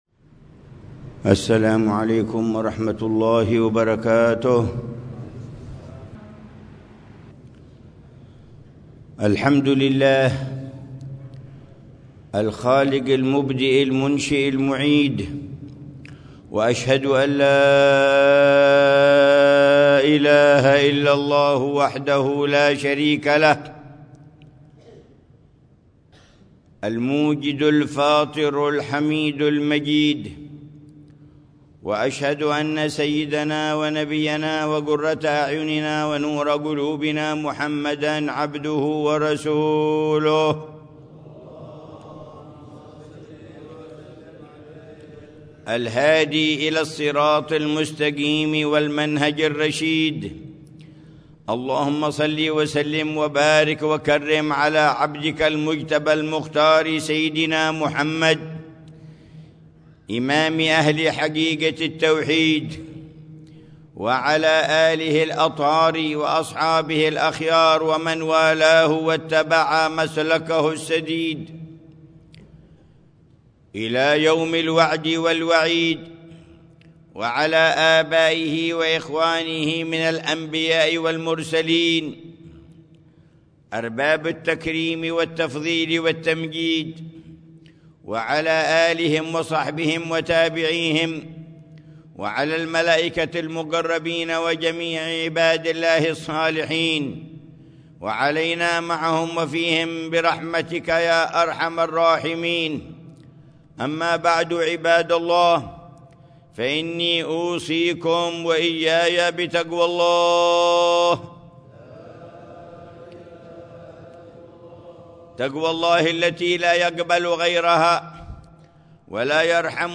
خطبة الجمعة
في جامع الإيمان بتريم